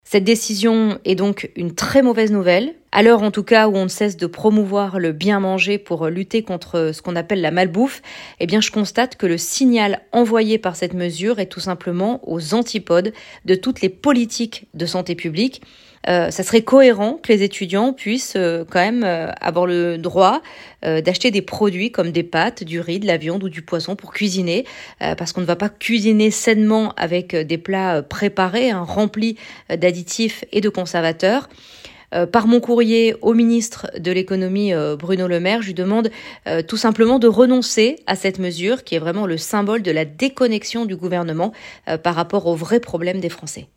Inadmissible pour la Sénatrice LR des Alpes-Maritimes, Alexandra Borchio, qui était à notre antenne. Elle y voit une injustice, notamment pour les étudiants salariés vis-à-vis de l'inflation.